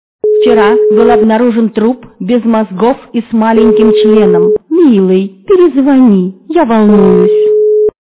» Звуки » Люди фразы » Вчера был обнаружен труп без... - Милый, перезвони, я волнуюсь
При прослушивании Вчера был обнаружен труп без... - Милый, перезвони, я волнуюсь качество понижено и присутствуют гудки.